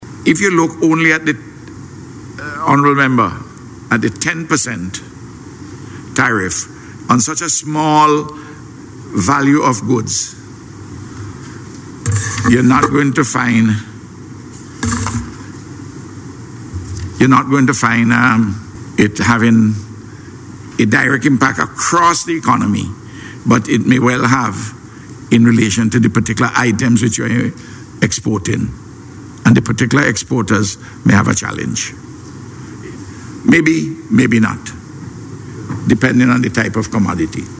He was at the time responding to a question in the House of Assembly regarding the blanket 10 percent tariff imposed by US President Donald Trump.